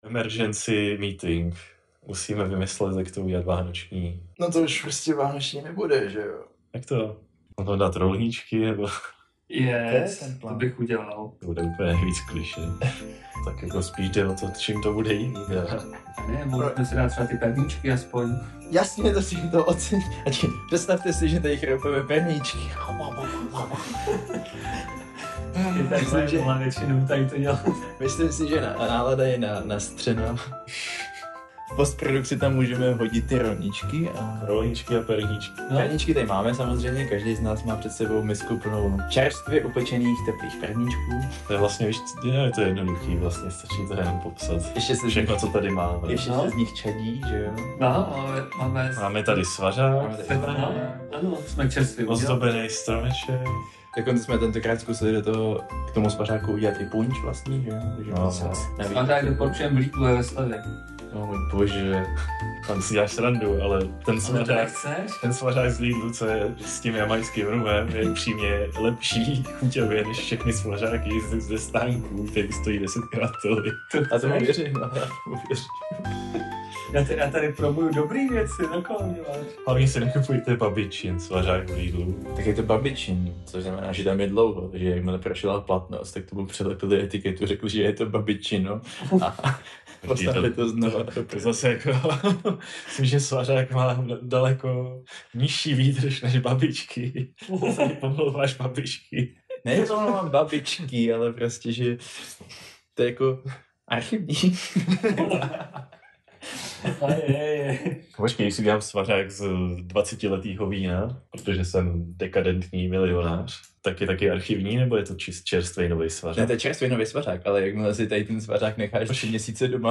Smějeme se každé blbosti a navzájem po sobě házíme plyšáky.